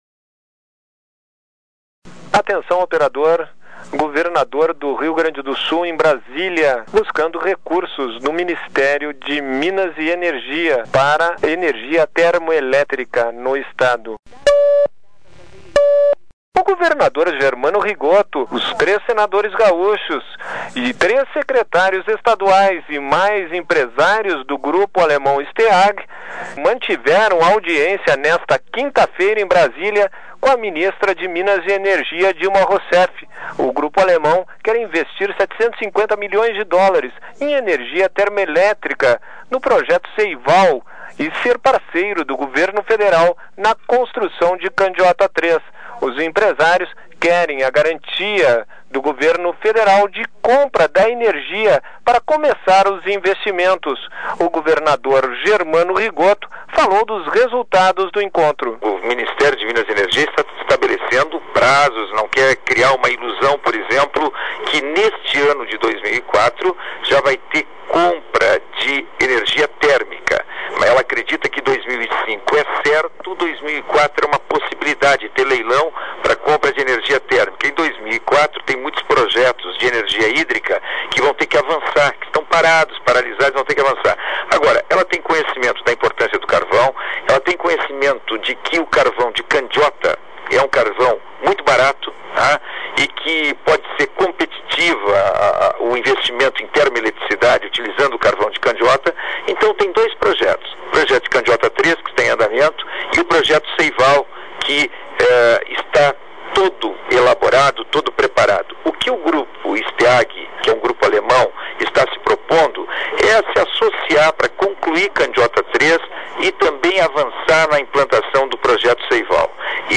O governador Germano Rigotto, os três senadores gaúchos e três secretarios estaduais e mais empresário do grupo alemão Steag mantiveram audiência, nesta 5ª feira, em Brasília, com a ministra de Minas e Energia Dilma Roussef. Sonora: governador Germano